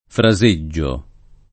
fraseggio [ fra @%JJ o ] s. m.; pl. ‑gi